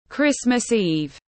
Đêm Giáng sinh tiếng anh gọi là Christmas Eve, phiên âm tiếng anh đọc là /ˌkrɪs.məs ˈiːv/
Christmas Eve /ˌkrɪs.məs ˈiːv/